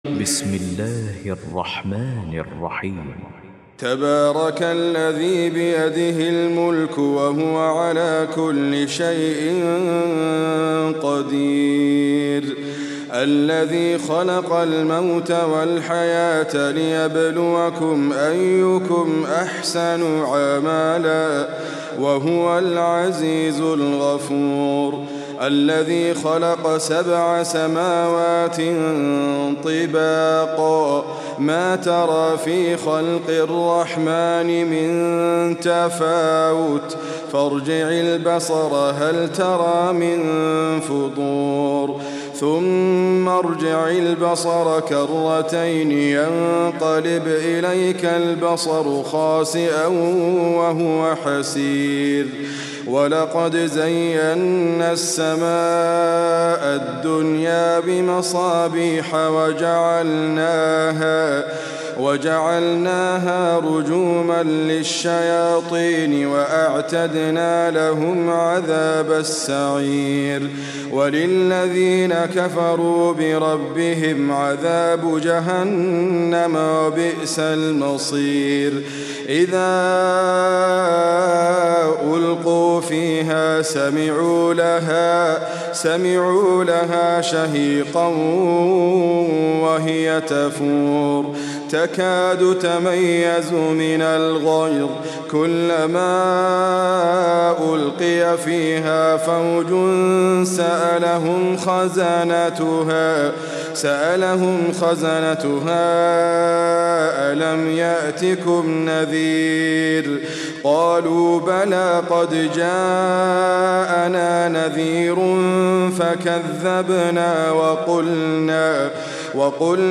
القران الكريم